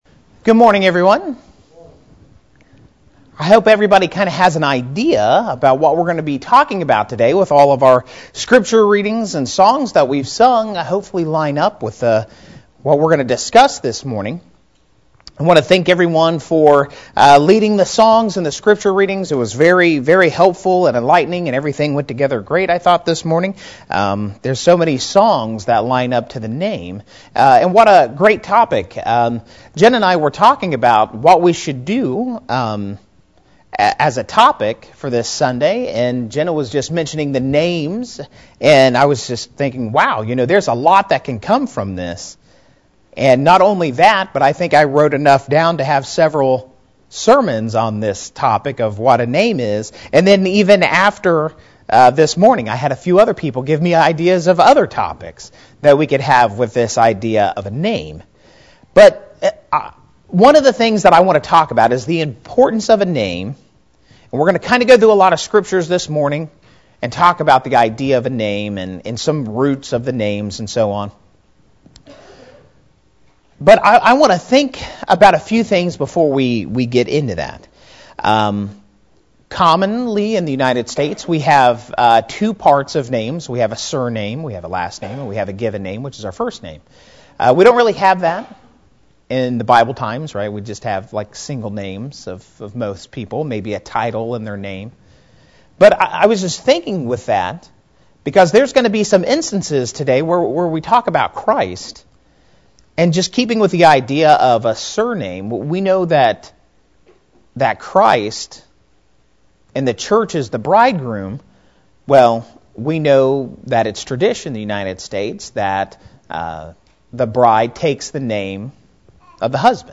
Then for the 2nd assembly the speaker presents a more detailed lesson on the same topic.